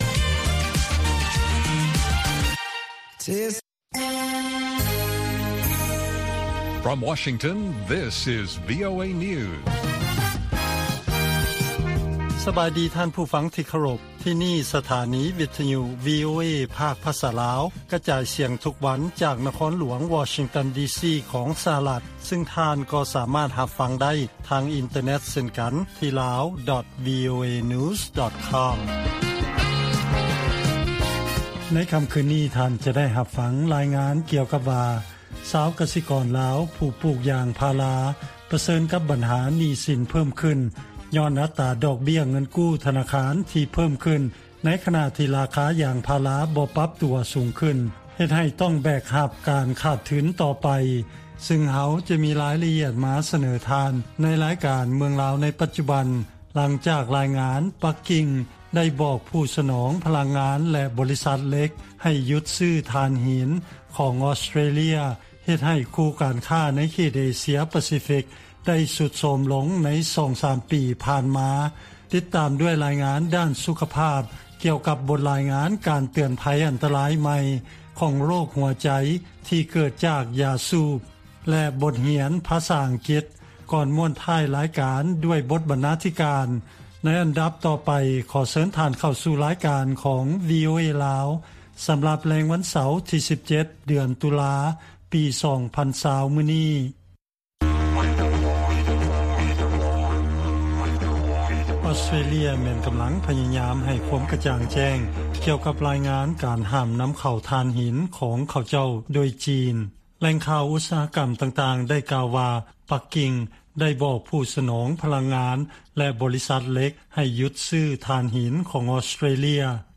ລາຍການກະຈາຍສຽງຂອງວີໂອເອ ລາວ
ວີໂອເອພາກພາສາລາວ ກະຈາຍສຽງທຸກໆວັນ. ຫົວຂໍ້ຂ່າວສໍາຄັນໃນມື້ນີ້ມີ: 1) ບັນດາຜູ້ປູກຢາງພາລາ ປະເຊີນໜ້າກັບໜີ້ສິນ ເນື່ອງຈາກດອກເບ້ຍ ທະນາຄານຂຶ້ນ. 2) 1 ໃນ 5 ຄົນໃດ ທີ່ເສຍຊີວິດໃນໂລກ ແມ່ນເປັນຍ້ອນ ການສູບຢາ. 3) ອອສເຕຣເລຍ ເປັນຫ່ວງ ກ່ຽວກັບ ລາຍງານທີ່ວ່າ ຈີນ ຫ້າມການນຳເຂົ້າຖ່ານຫີນ ແລະຂ່າວສໍາຄັນອື່ນໆອີກ.